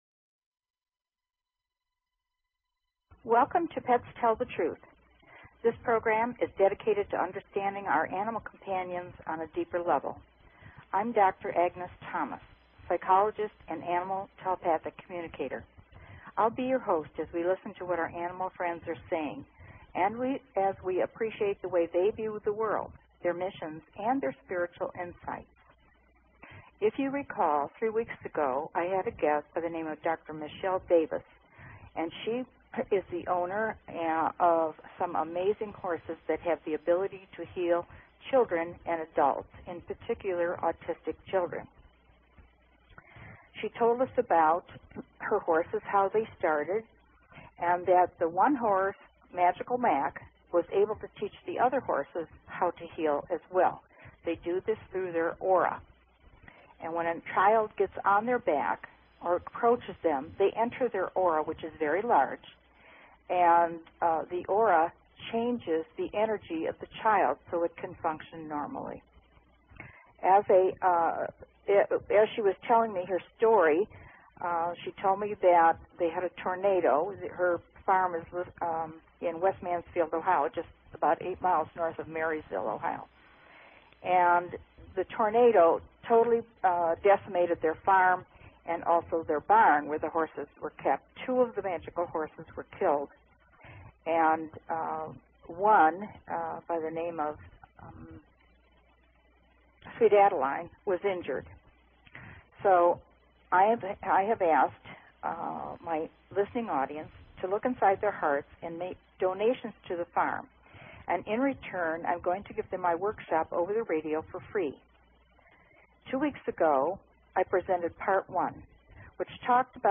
Talk Show Episode, Audio Podcast, Pets_Tell_The_Truth and Courtesy of BBS Radio on , show guests , about , categorized as